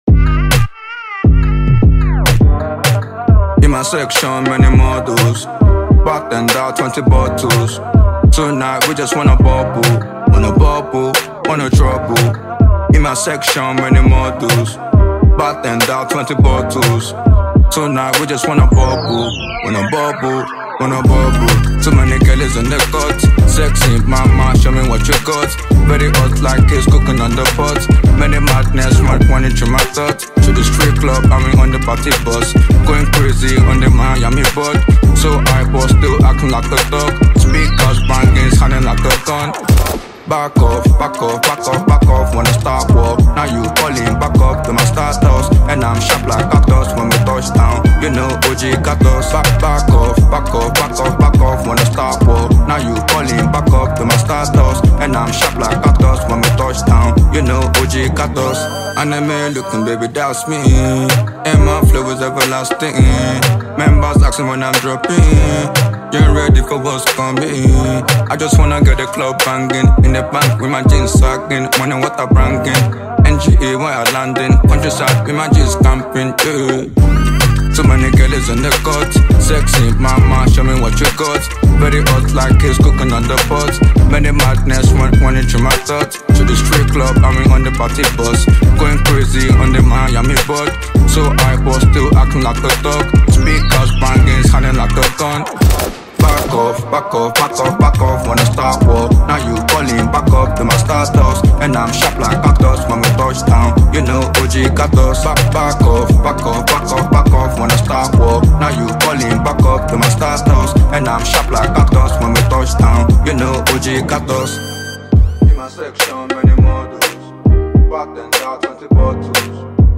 Nigerian Hip-Hop lynchpin